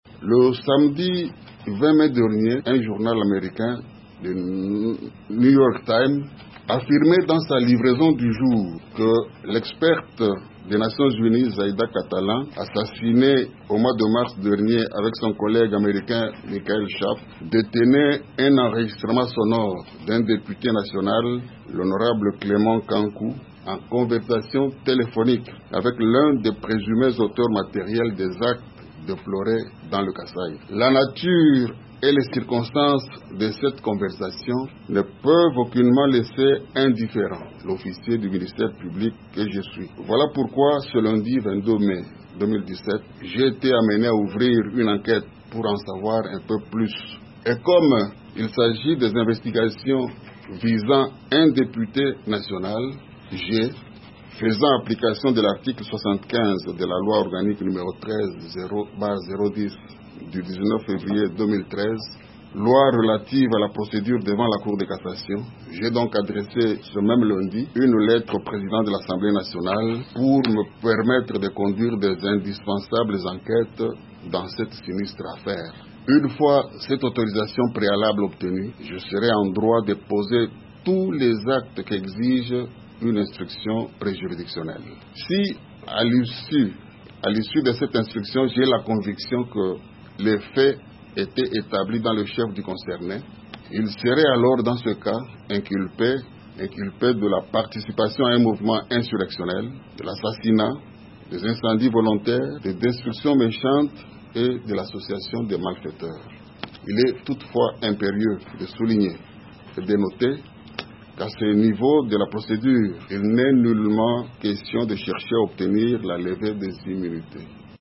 Flory Kabange Numbi au micro de Top Congo